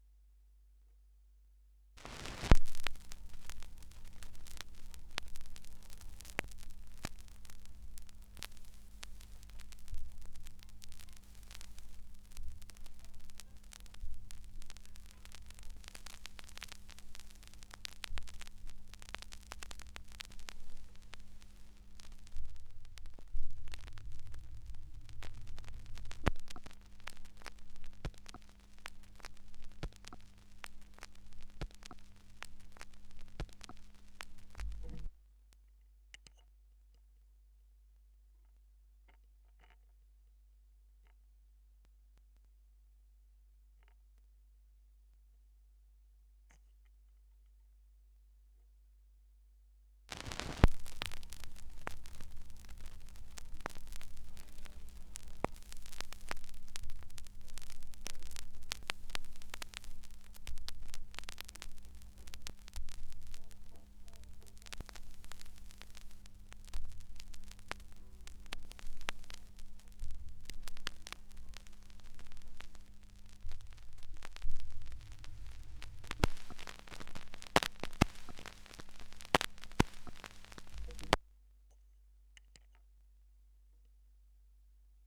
2017 Schallplattengeräusche (3)
Leere Phrasen (Leerstellen auf Schallplatten, die digitalisiert werden.)
Digitalisierung: Thorens TD 165 Special, Orthophon Pro System, Tascam HD-P2 (24 Bit, 48 KHz), Audacity